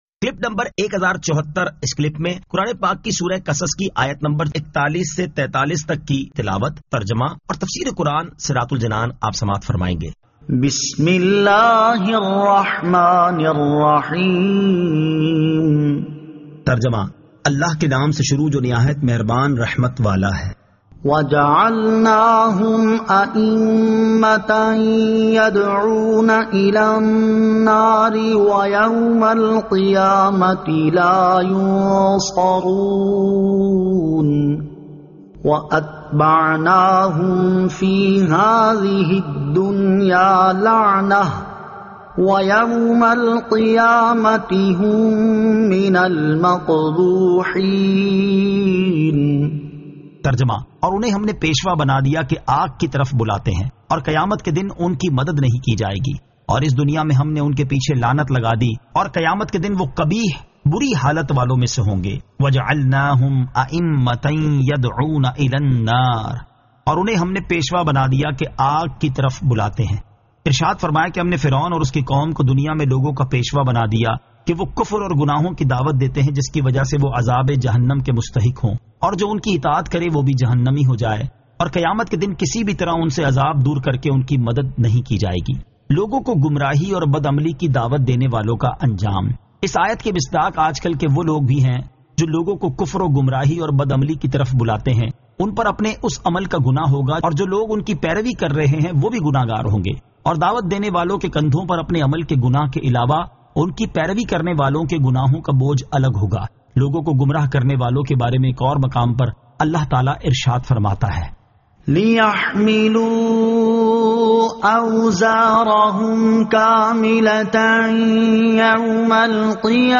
Surah Al-Qasas 41 To 43 Tilawat , Tarjama , Tafseer